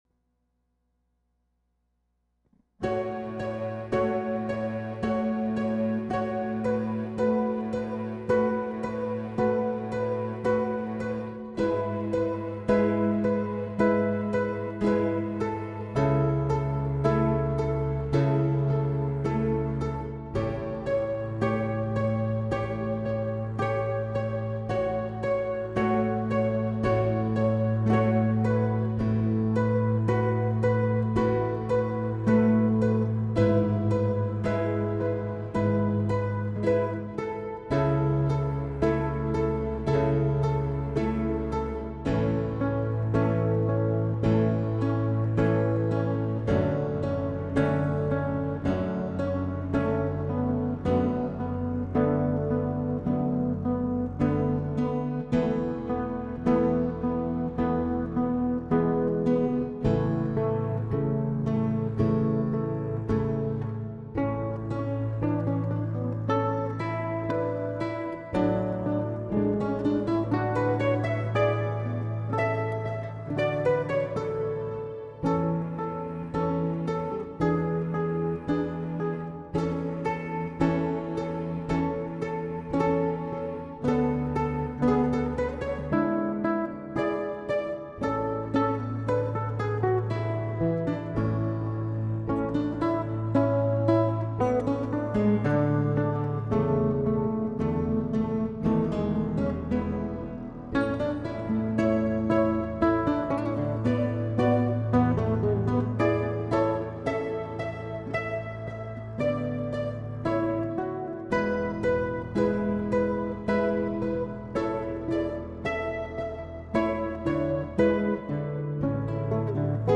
• Sevillano Flamenco